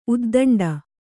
♪ uddaṇḍa